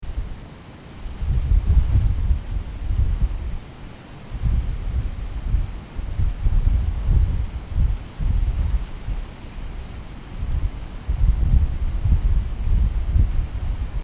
atmosWind.mp3